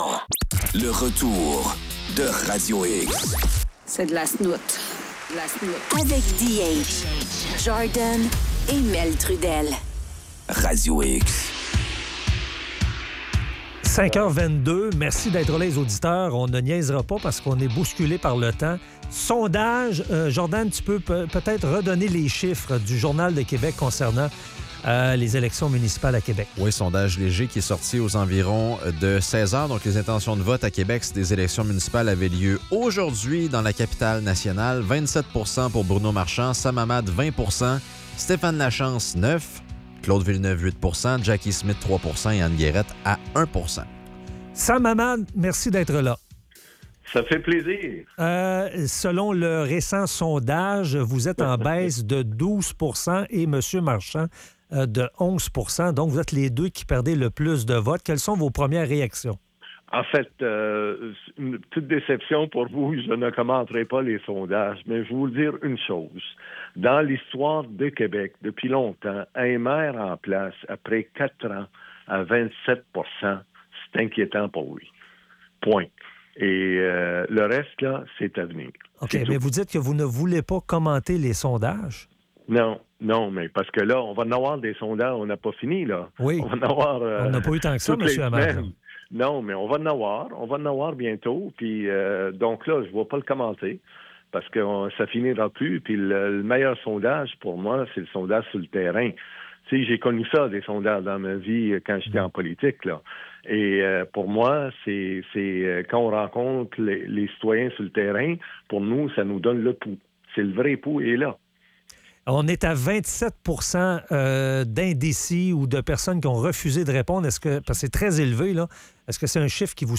discute avec le chef de Leadership Québec, Sam Hamad.